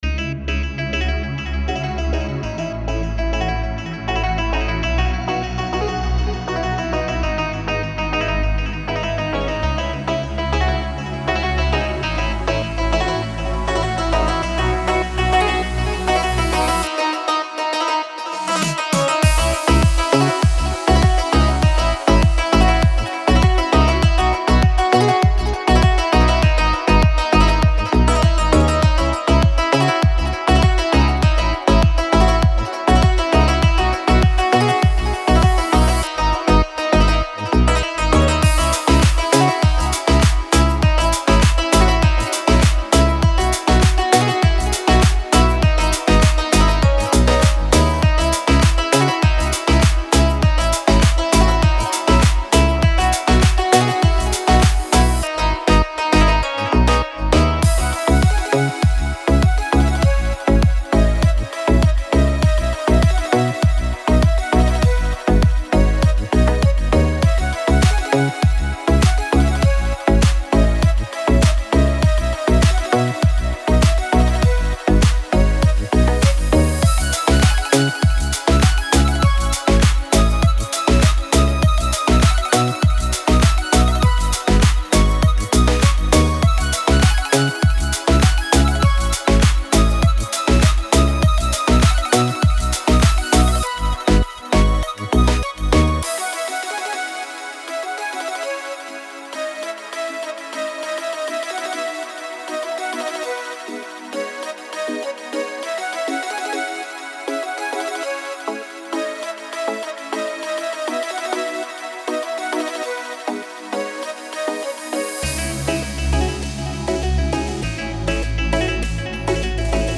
دیپ هاوس , ریتمیک آرام , موسیقی بی کلام